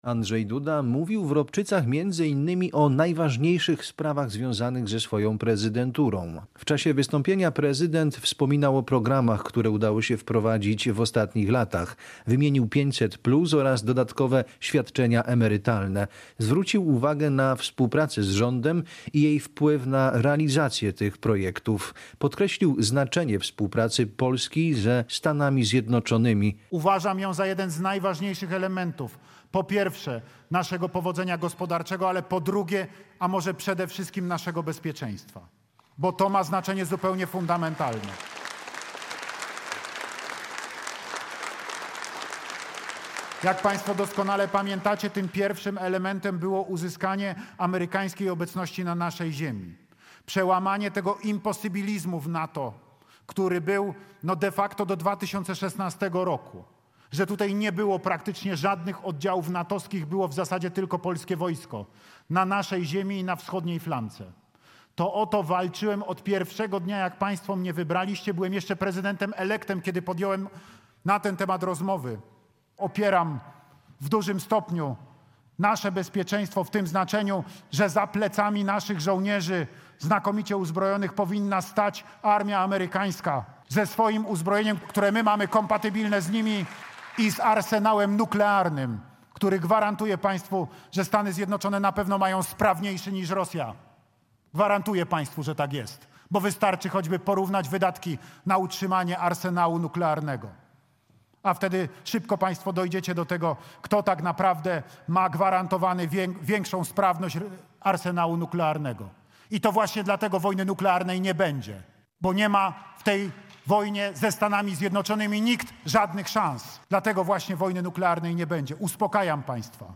Podczas wizyty w czwartek (20.02) w Ropczycach mówił o obecności wojsk amerykańskich w Polsce oraz jej wpływie na obronność.
Podczas wystąpienia mówił także o programach społecznych, które wprowadzono w ostatnich latach.